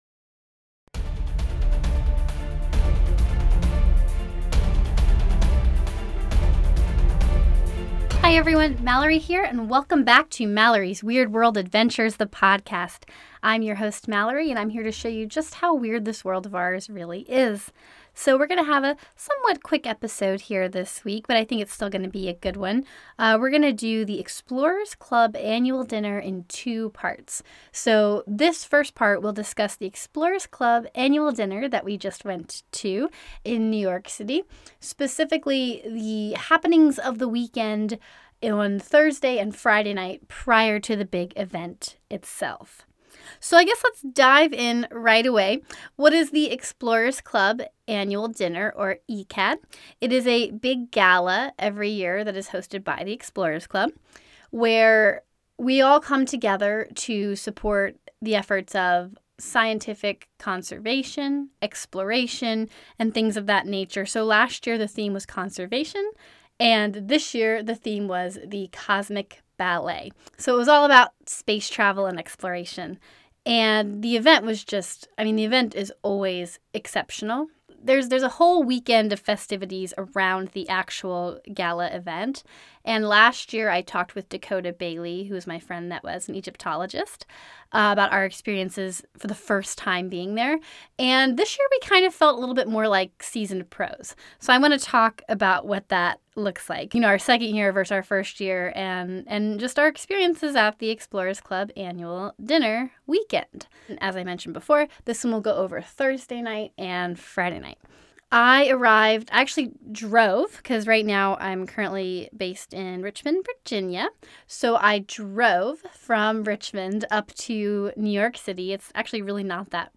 so bear with the short episode and raspy voice